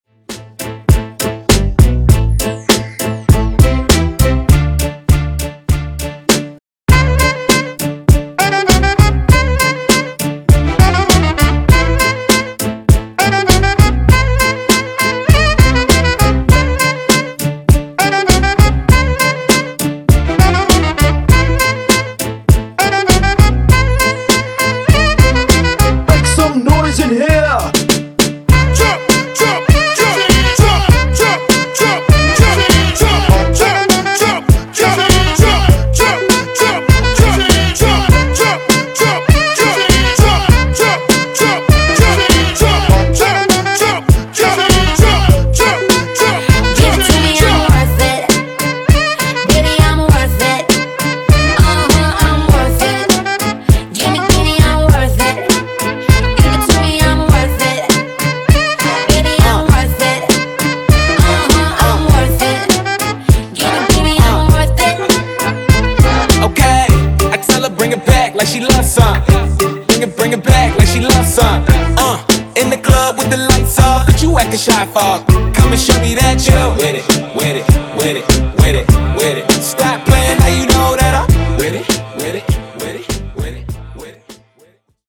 Genres: PARTY BREAKS , R & B
Clean BPM: 100 Time